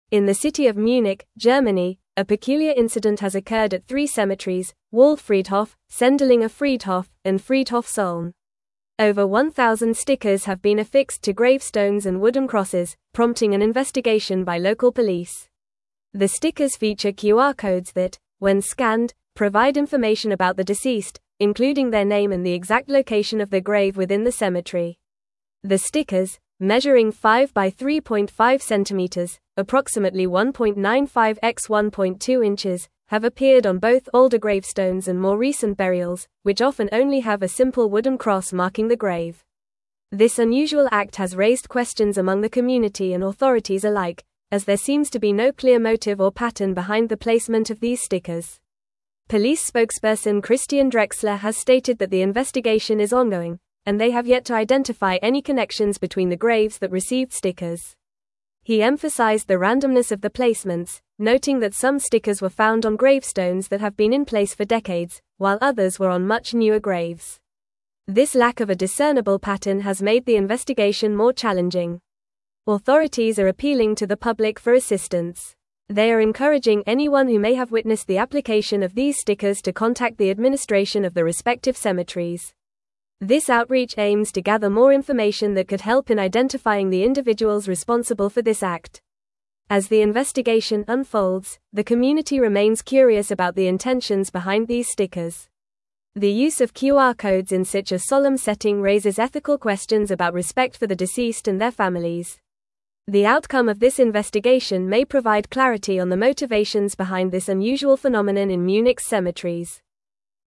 Fast
English-Newsroom-Advanced-FAST-Reading-QR-Code-Stickers-Appear-on-Gravestones-in-Munich.mp3